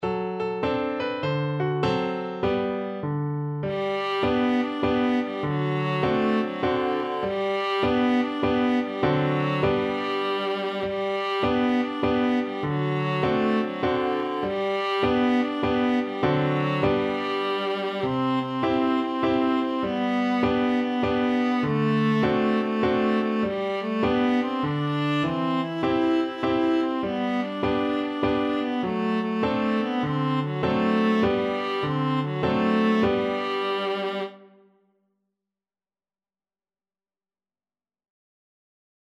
Traditional Music of unknown author.
With a swing
3/4 (View more 3/4 Music)
D4-E5